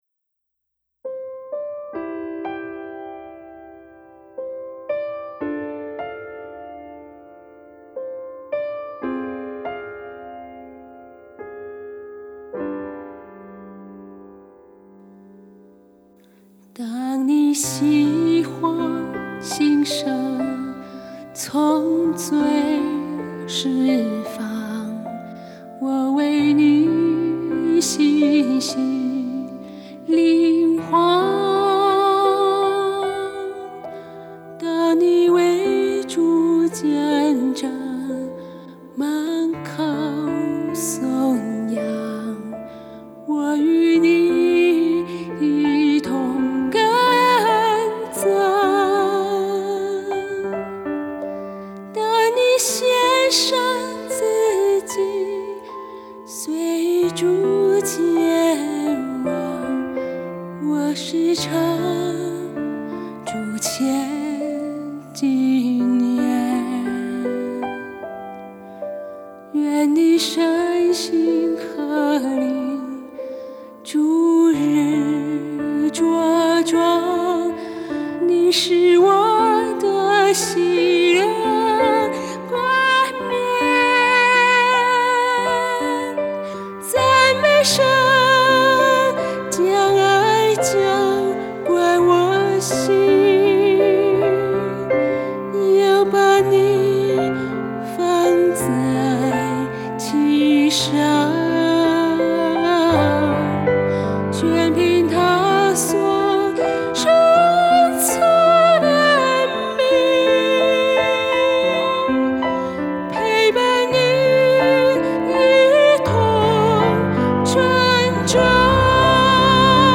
鋼琴、Midi演奏
錄音室：動物園錄音室